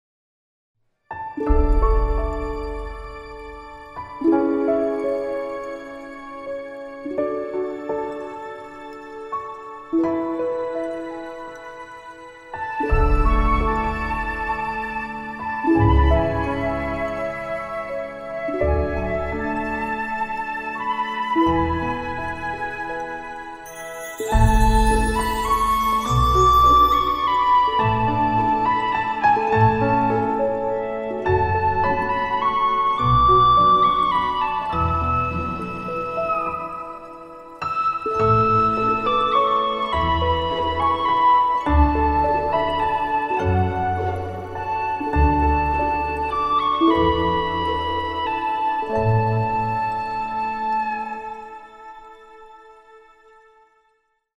小遊戲背景音樂：